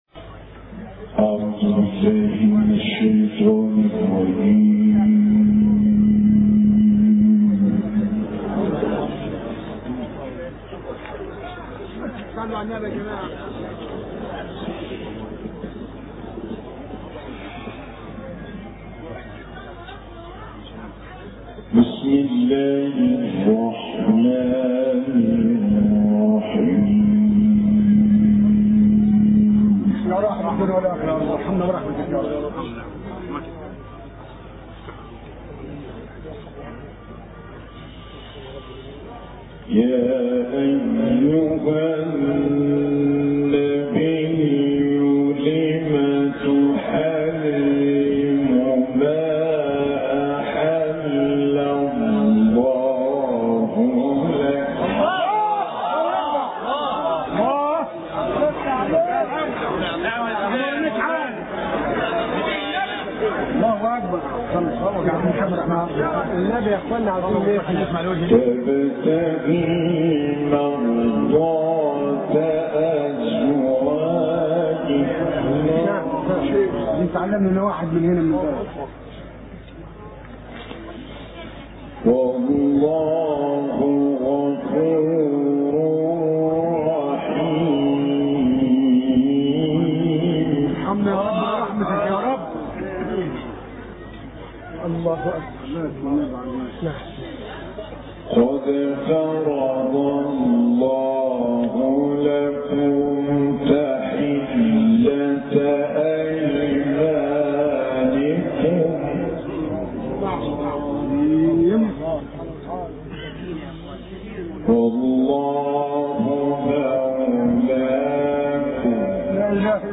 Recitations